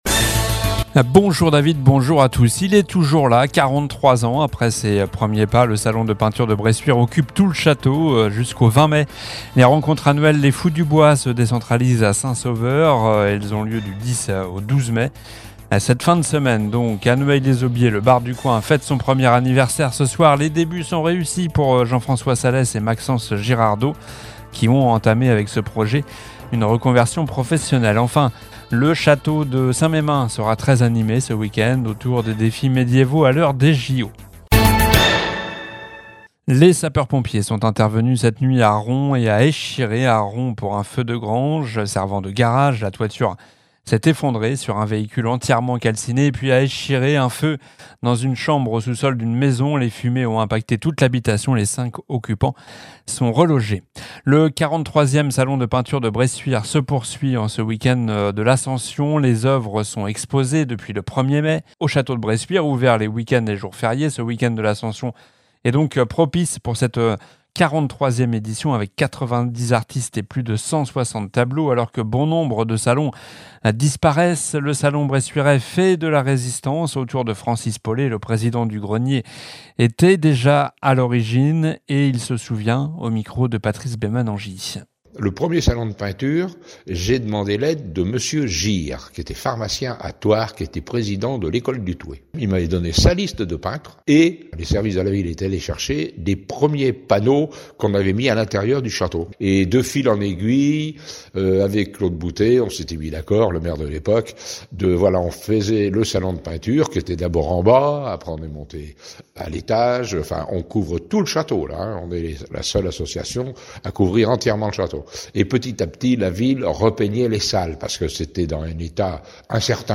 Journal du mardi 7 mai (midi)